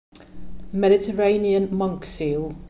pronunciation)